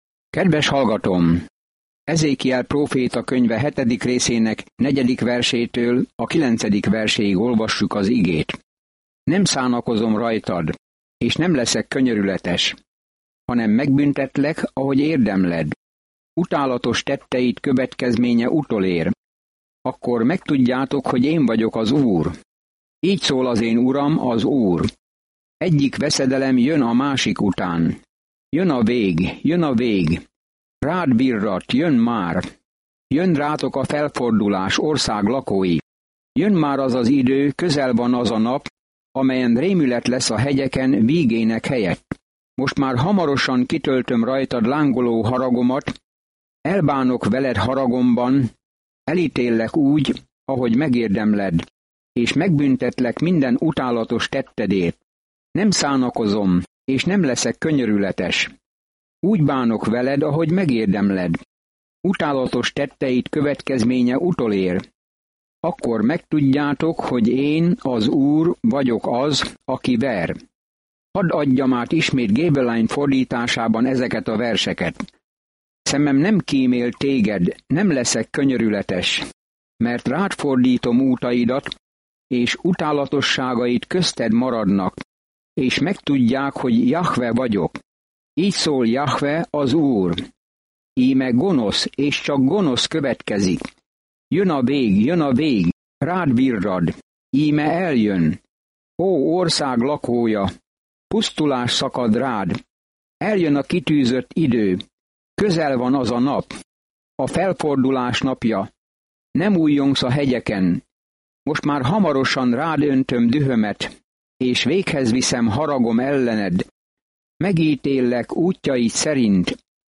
Szentírás Ezékiel 7:4-27 Ezékiel 8:1-4 Nap 5 Olvasóterv elkezdése Nap 7 A tervről Az emberek nem hallgattak Ezékiel figyelmeztető szavaira, hogy térjenek vissza Istenhez, ezért ehelyett az apokaliptikus példázatokat adta elő, és ez meghasította az emberek szívét. Napi utazás Ezékielben, miközben hallgatja a hangos tanulmányt, és olvassa kiválasztott verseket Isten szavából.